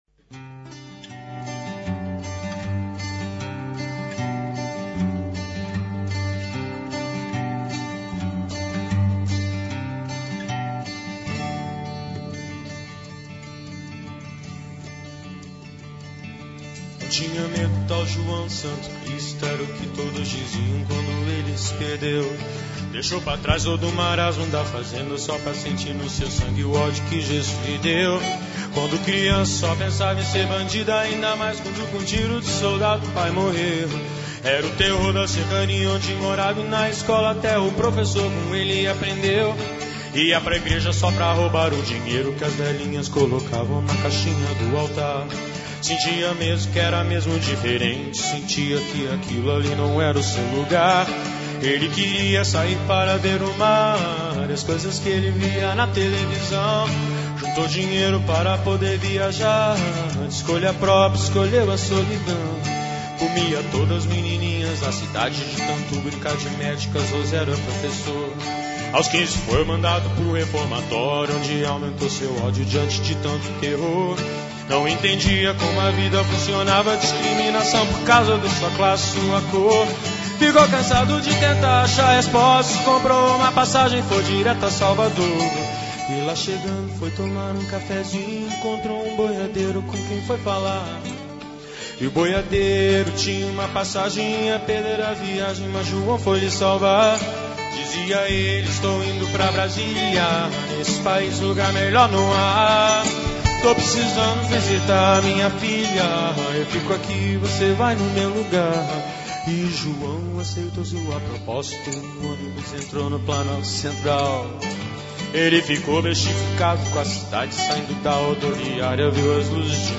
Entrevista a César Troncoso